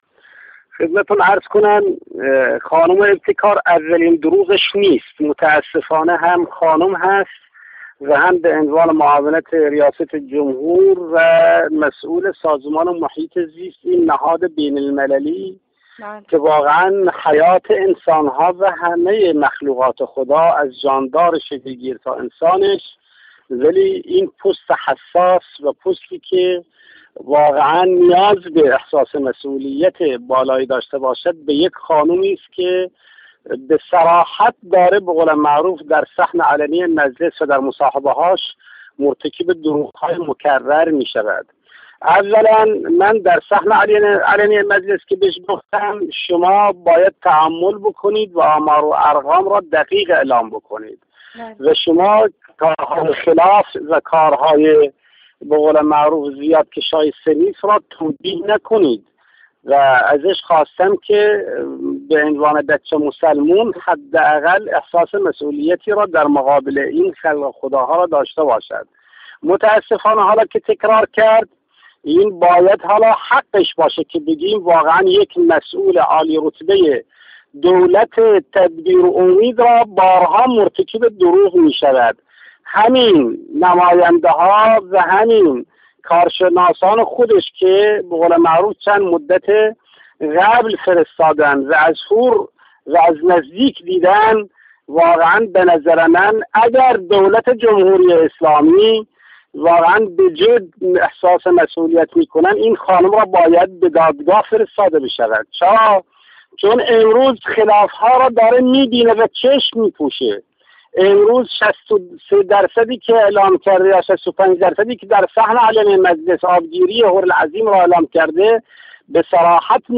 ساعدی، در گفت‌وگو با خبرنگار اجتماعی تسنیم در واکنش به اظهارات صبح امروز ابتکار گفت: متأسفانه آبگیری 62 درصدی هورالعظیم اولین دروغ خانم ابتکار نیست.